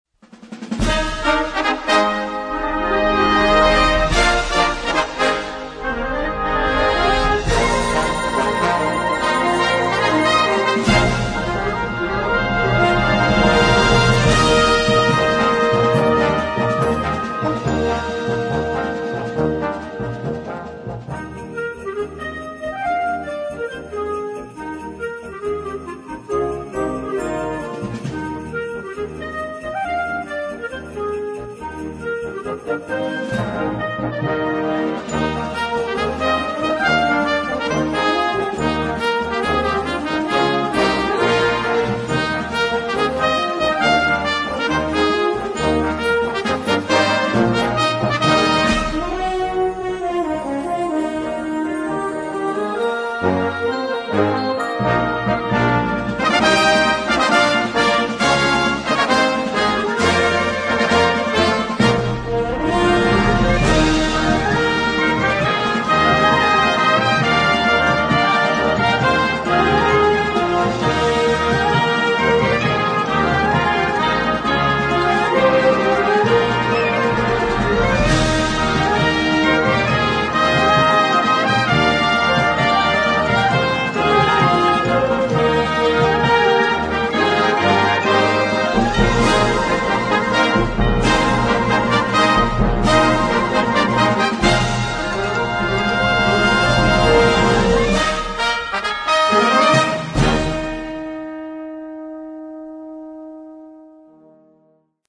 Bold and dynamic
Partitions pour orchestre d'harmonie.